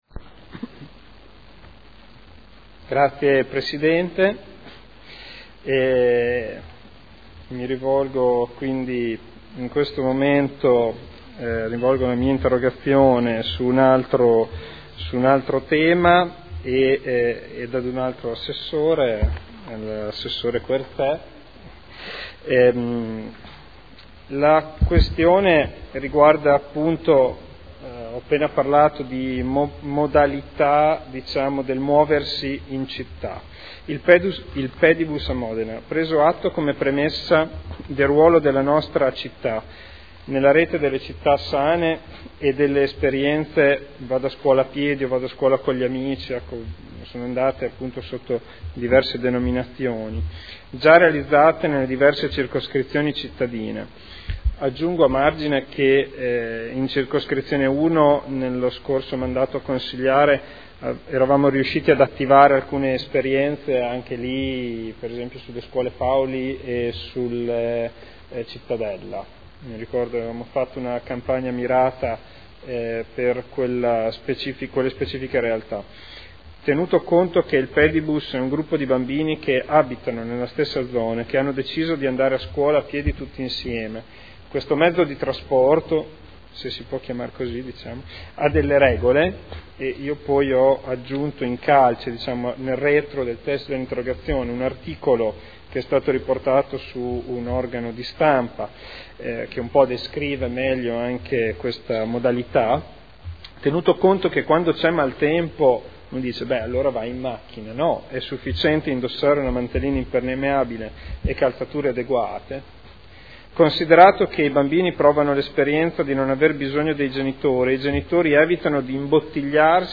Seduta del 25/06/2012. Interrogazione del consigliere Ricci (Sinistra per Modena) avente per oggetto: “Il Pedibus a Modena”